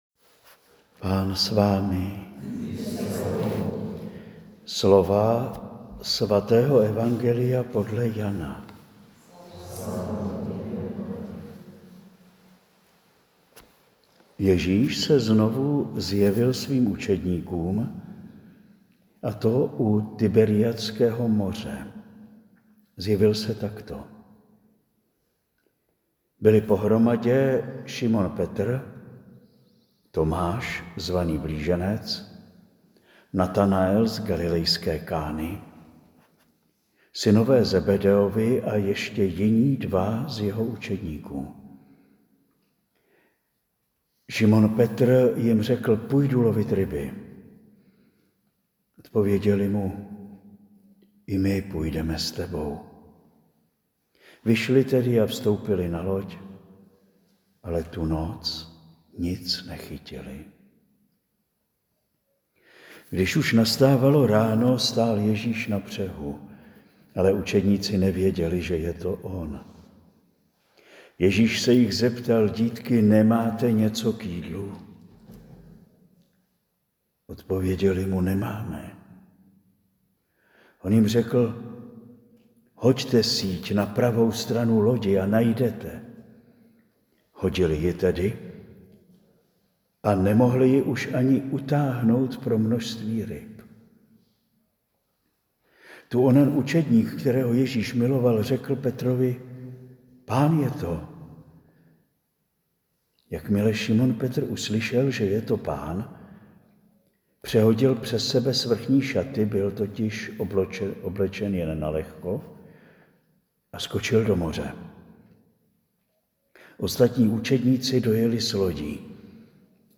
Záznam homilie z 3. neděle velikonoční (4. 5. 2025) si můžete stáhnout na tomto odkazu.